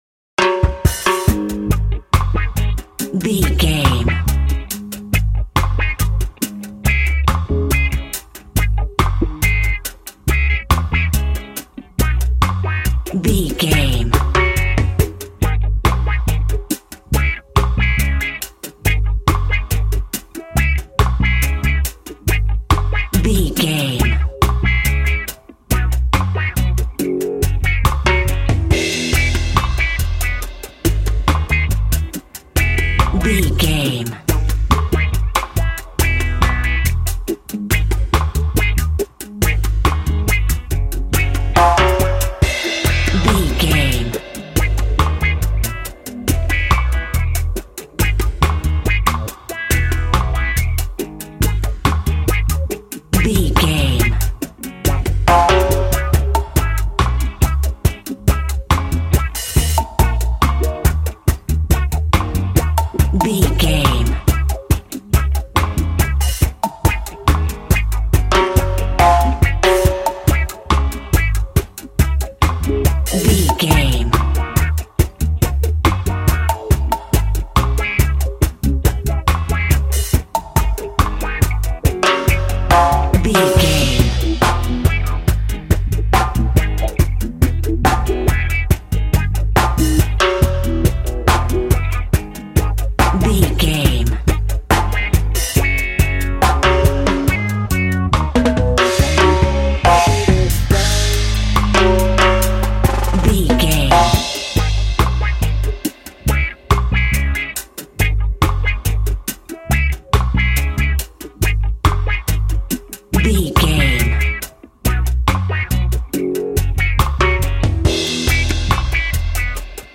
Aeolian/Minor
A♭
cheerful/happy
mellow
drums
electric guitar
percussion
horns
electric organ